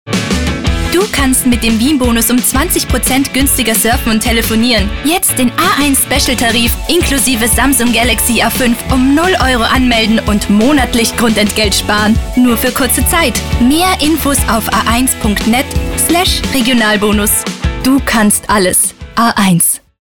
Lektor
austriacki
profesjonalny lektor filmowy dostępny od ręki.